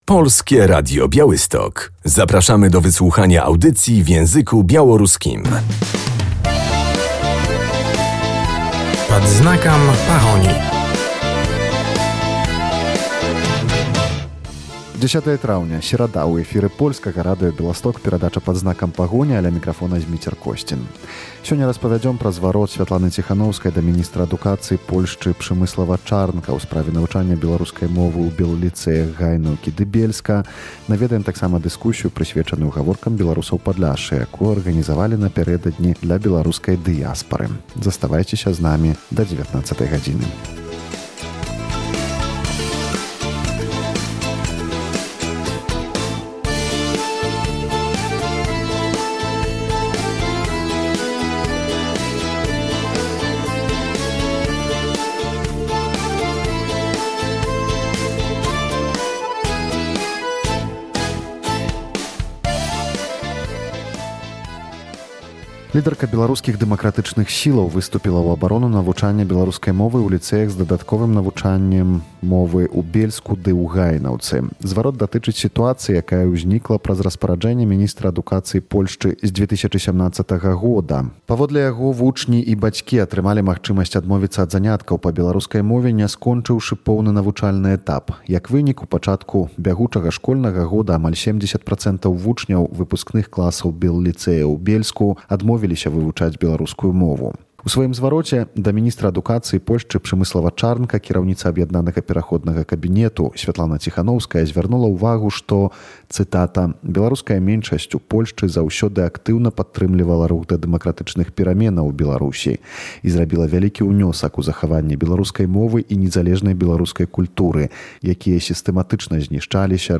W dzisiejszej audycji Pad znakam Pahoni odwiedzimy jedną z dyskusji.